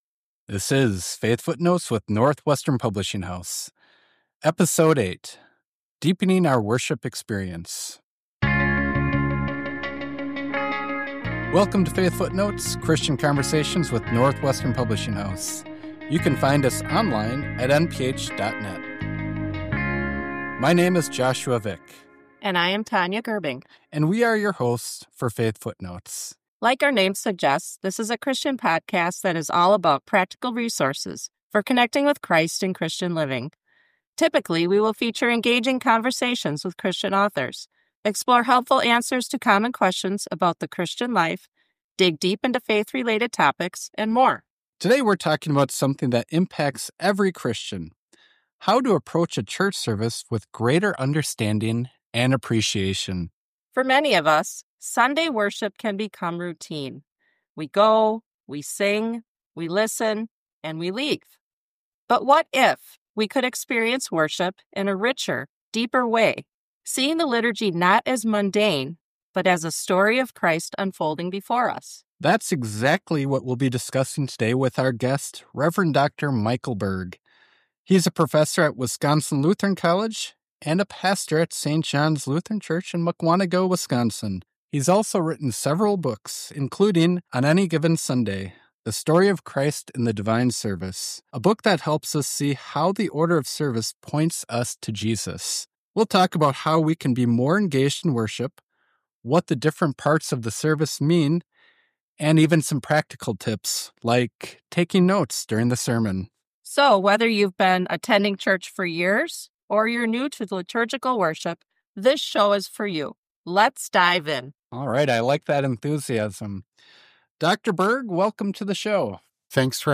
Whether you’re new to liturgical worship or have been attending church for years, this conversation will help you see the divine service for what it truly is: Christ serving us through Word and sacrament.